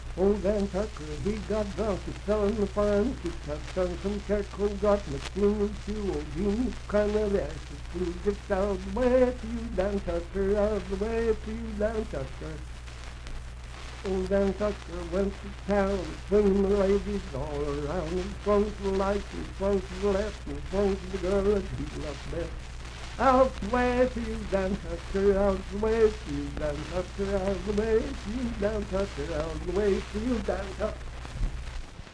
Unaccompanied vocal music
Dance, Game, and Party Songs
Voice (sung)
Grant County (W. Va.)